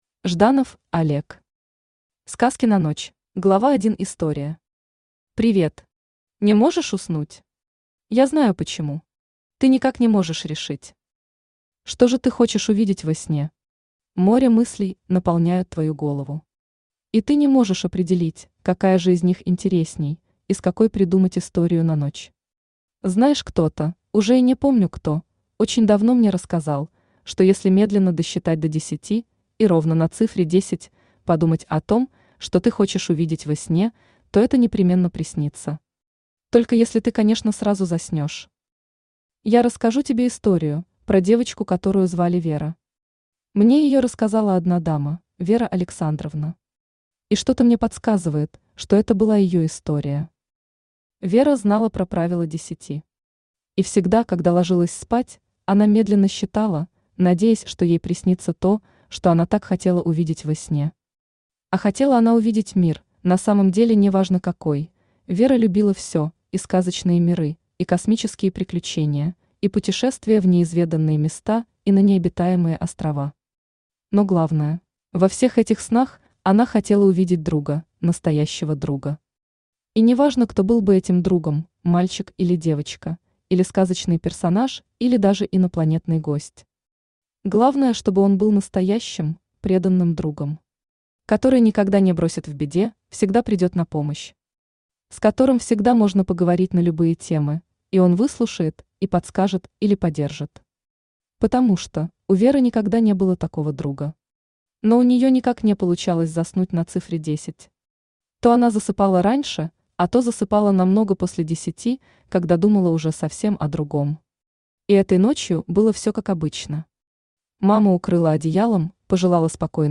Аудиокнига Сказки на ночь | Библиотека аудиокниг
Aудиокнига Сказки на ночь Автор Жданов Геннадьевич Олег Читает аудиокнигу Авточтец ЛитРес.